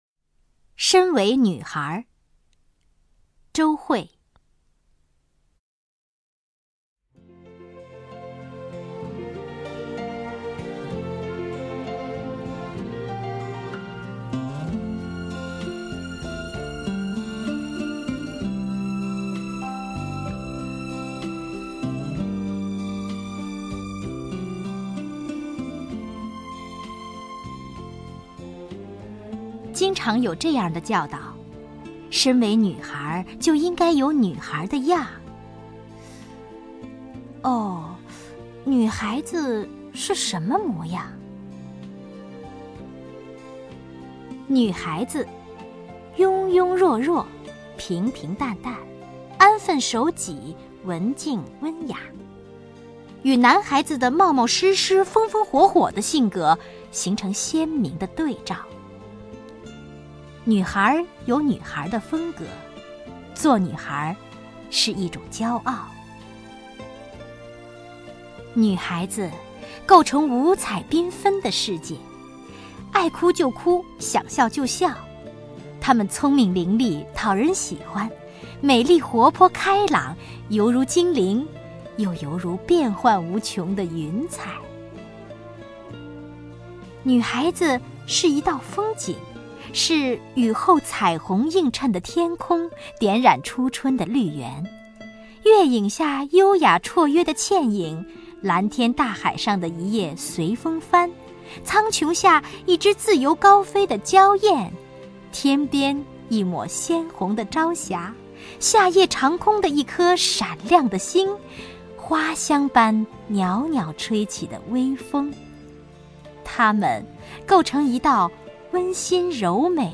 王雪纯朗诵：《身为女孩》(周惠)
名家朗诵欣赏 王雪纯 目录